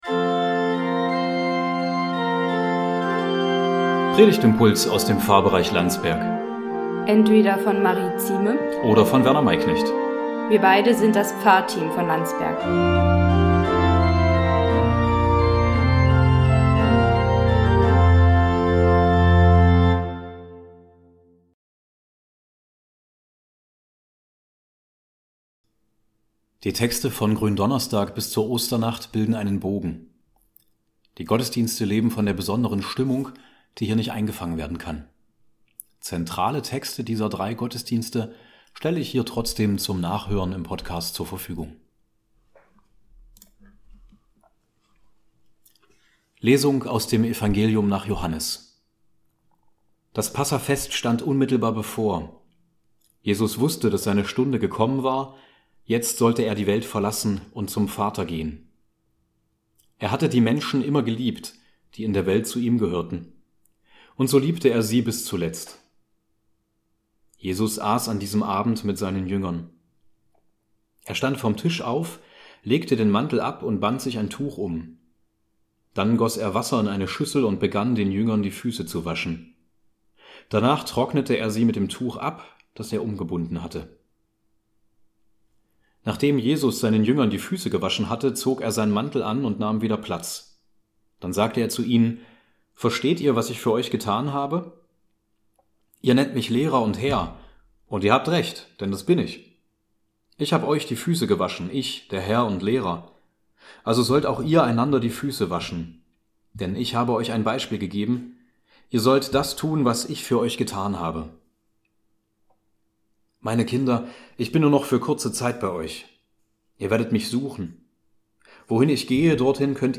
Predigtimpulse aus dem Pfarrbereich Landsberg